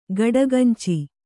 ♪ gaḍaganci